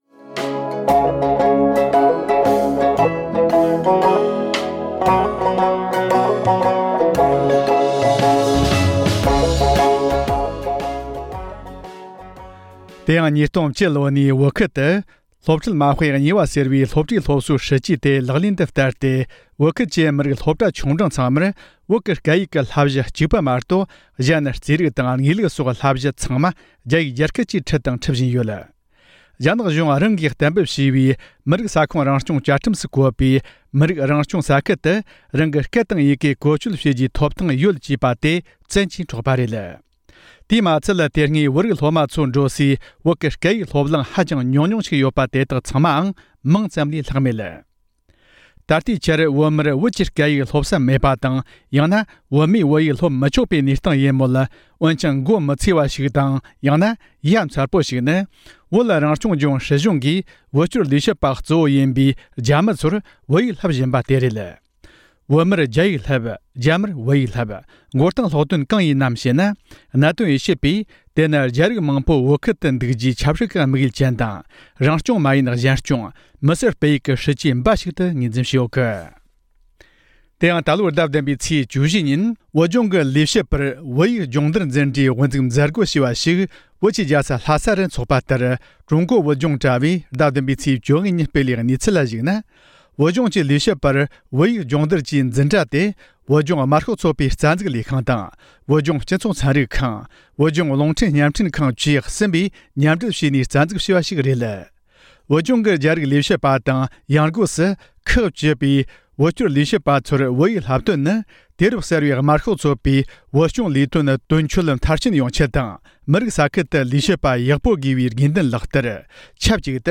བོད་རང་སྐྱོང་ལྗོངས་སྲིད་གཞུང་གིས་བོད་སྐྱོར་ལས་བྱེད་པ་དང་རྒྱ་རིགས་ལས་བྱེད་པར་བོད་ཡིག་སྦྱོང་བརྡར་གྱི་འཛིན་གྲྭ་བཙུགས་བོད་ཡིག་བསླབ་བཞིན་ཡོད་པའི་ཐད་གནད་དོན་དབྱེ་ཞིབ་པས་བསམ་ཚུལ་གསུངས་བ།